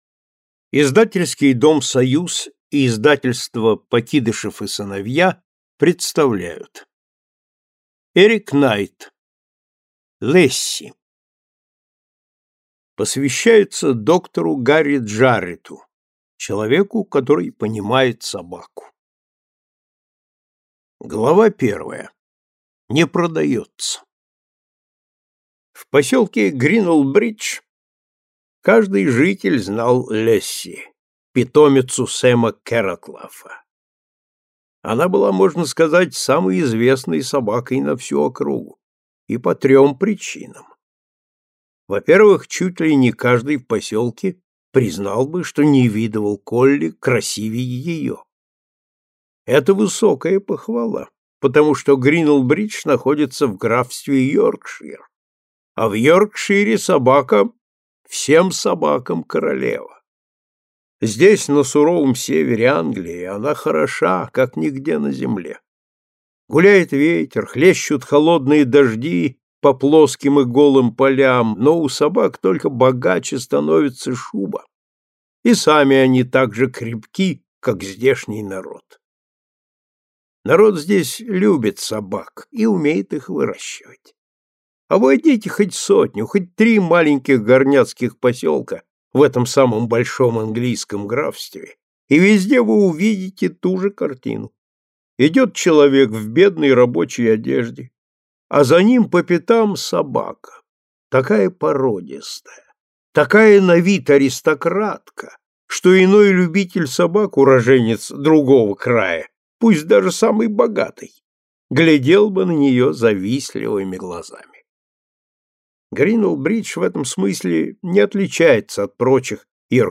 Аудиокнига Лесси | Библиотека аудиокниг
Прослушать и бесплатно скачать фрагмент аудиокниги